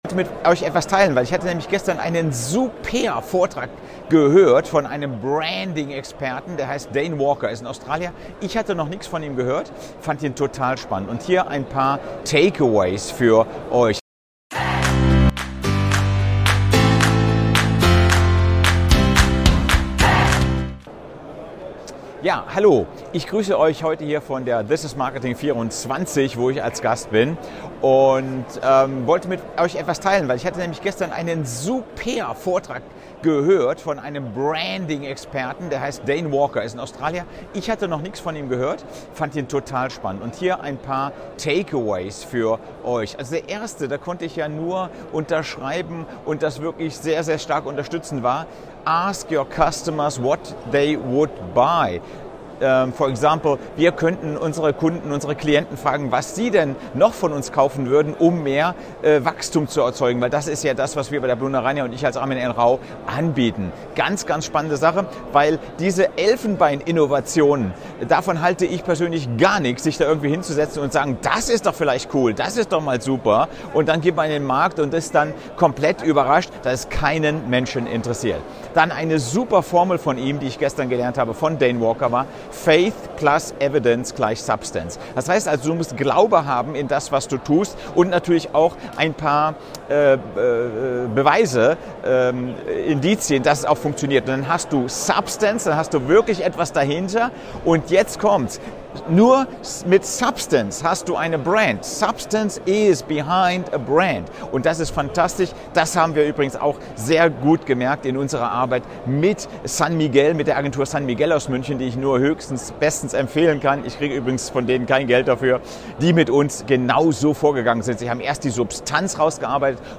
Nr. 8 - Jetzt das Umsatzloch verlassen: Wie Branding den Unterschied macht (Live von der TIM 2024)